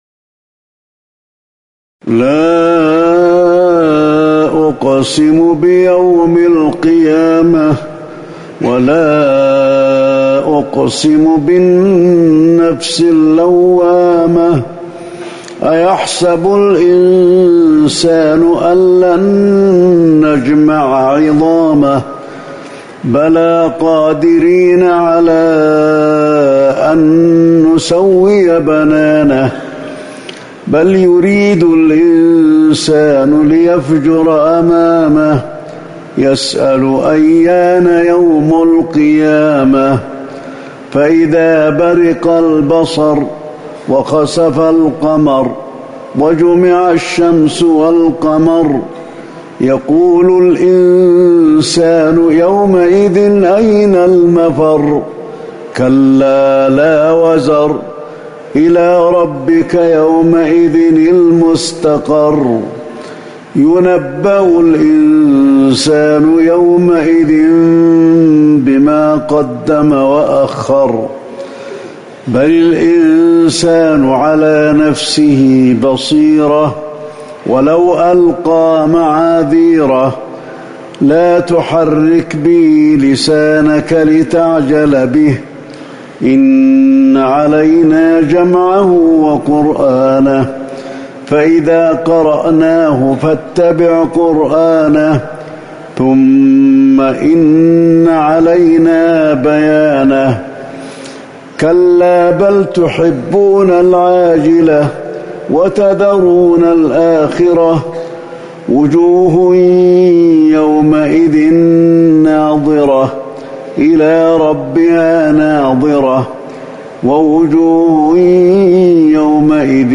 صلاة الفجر ١٤٤١/١/١ سورتي القيامة والبلد | Fajr prayer from Surah Al-Qiyamah & Al-Balad > 1441 🕌 > الفروض - تلاوات الحرمين